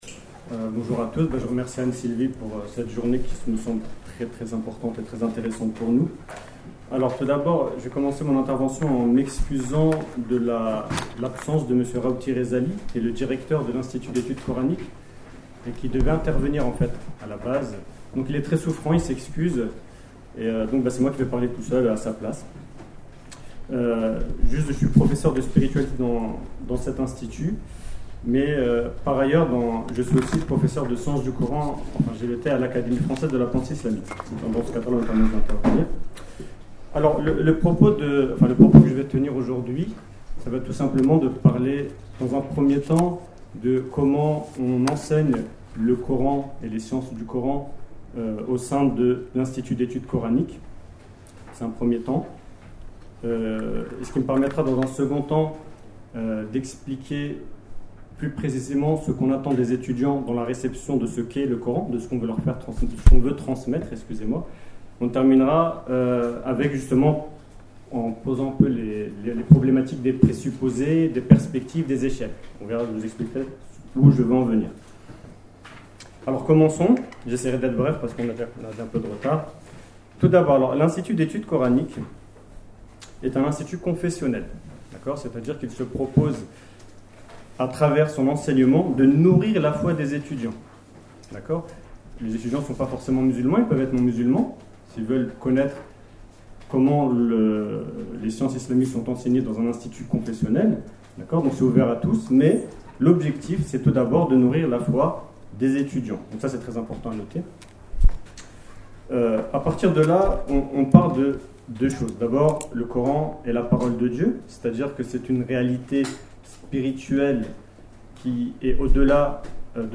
Accédez à l'intégralité de la conférence en podcast audio dans l'onglet téléchargement